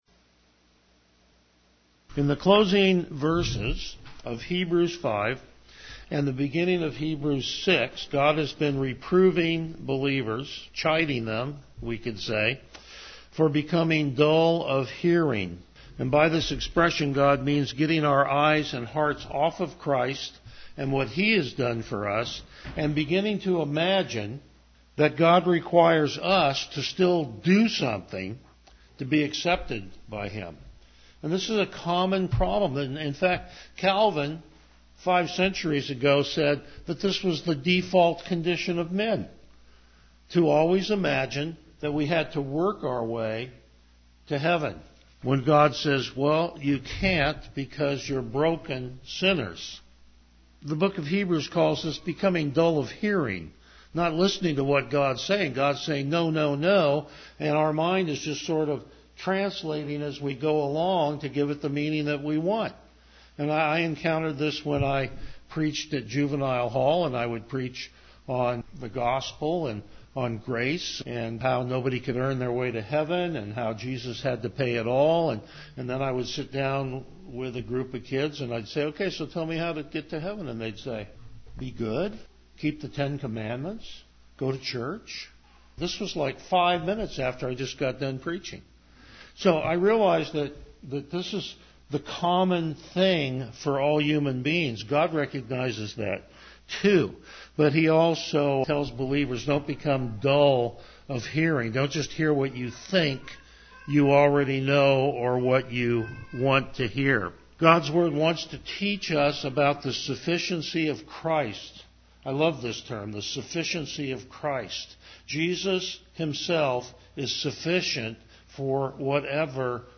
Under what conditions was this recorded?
Passage: Hebrews 6:4-8 Service Type: Morning Worship